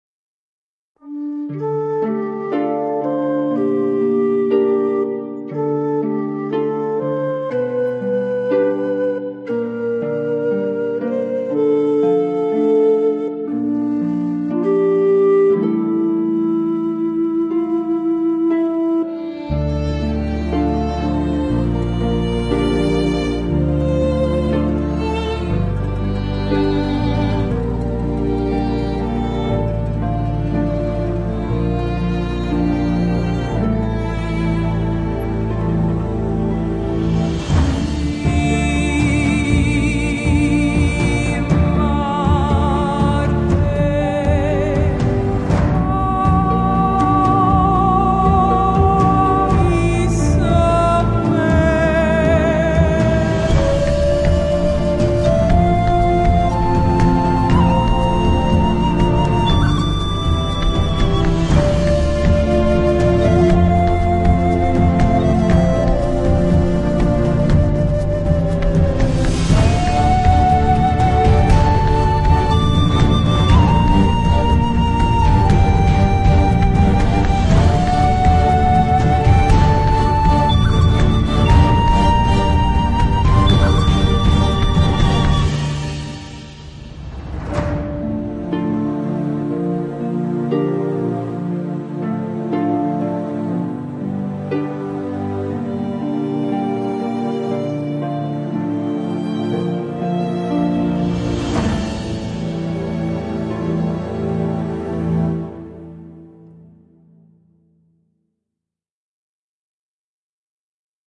Instrumental with NO COMPOSER mentioned!!!!